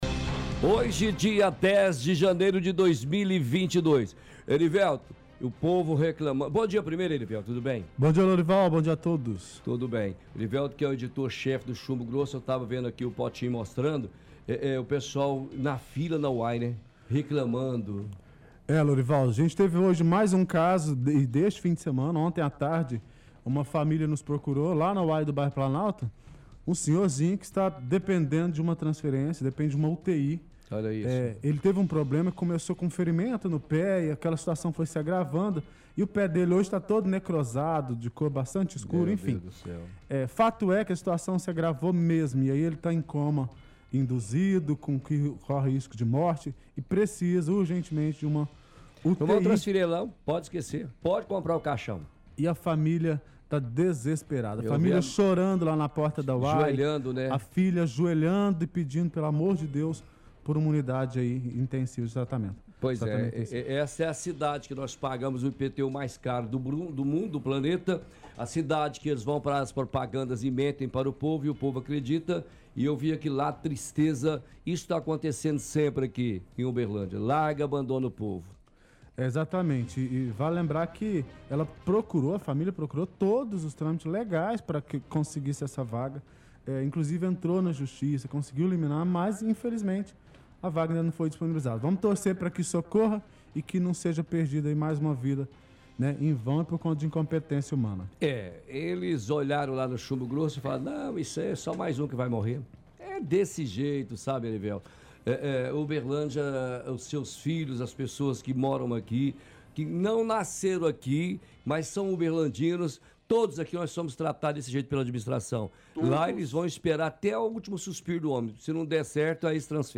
conversa com repórter do Chumbo Grosso